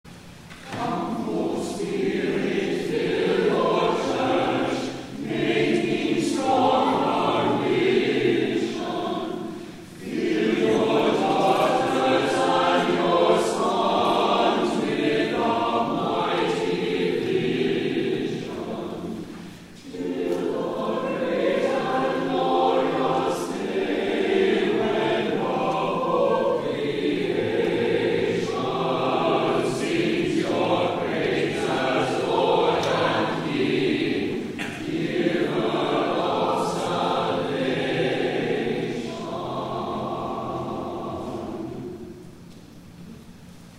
DAY OF PENTECOST
*THE CHORAL RESPONSE